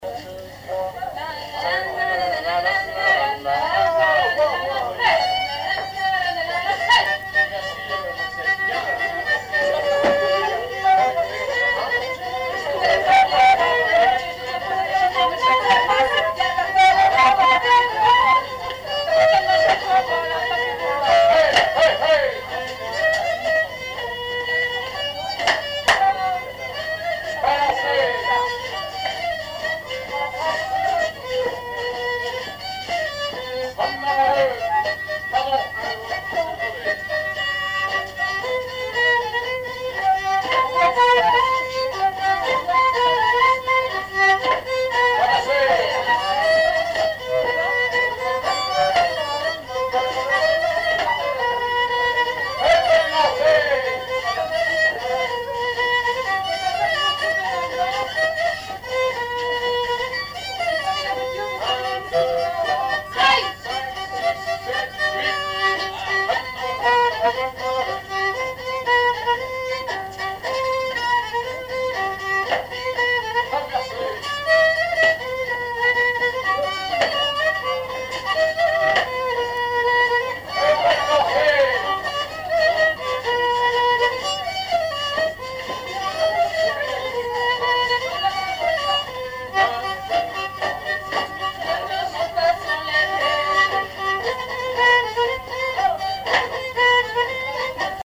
danse : branle : avant-deux
Répertoire d'un bal folk par de jeunes musiciens locaux
Pièce musicale inédite